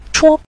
chuo5.mp3